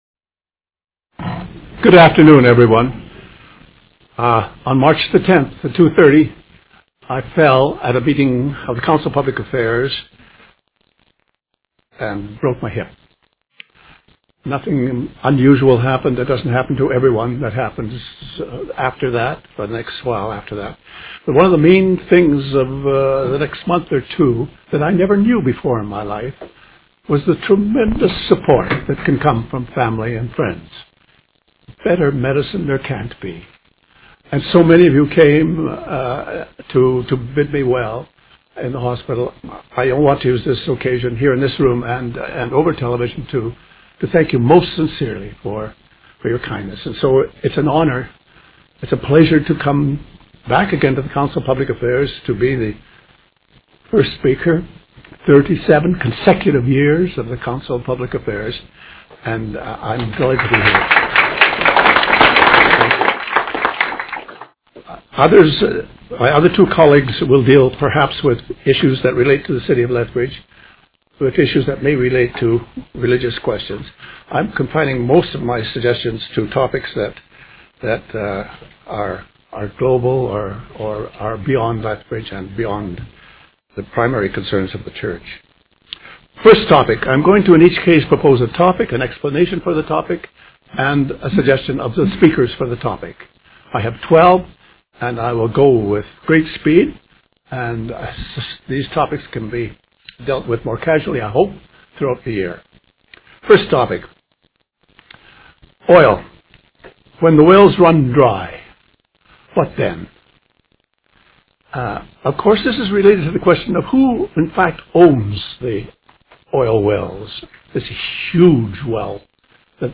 What makes your short list of urgent issues someone should deal with or talk about? We have invited three bright and thoughtful people to each speak briefly about pending issues as they see them, and to help us think in fresh ways about these matters.